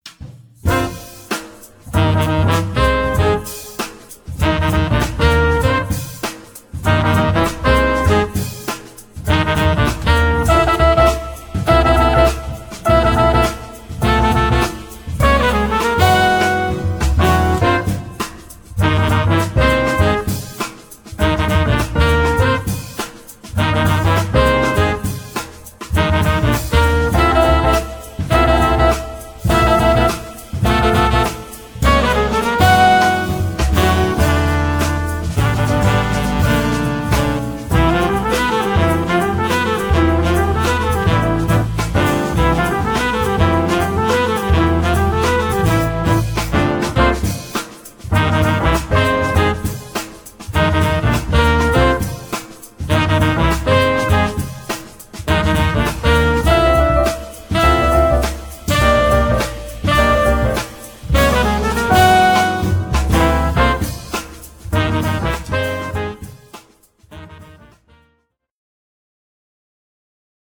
Jazz na CD
trumpet & flugelhorn
soprano & tenor saxophones
piano & Fender Rhodes
bass
drums